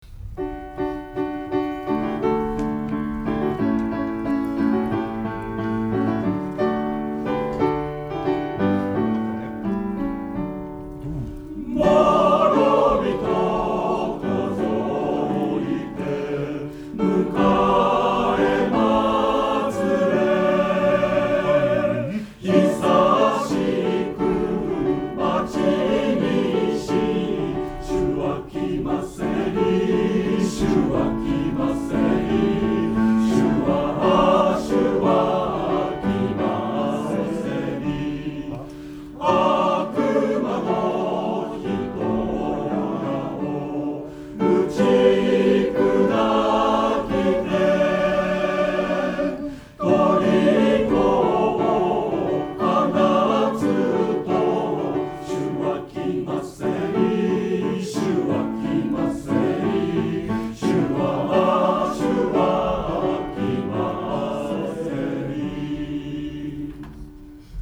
練習場所：明石市立勤労福祉会館　2階第5会議室（明石市）
出席者：37名（sop12、alt11、ten8、bass6）
→クリスマスメドレー4曲通し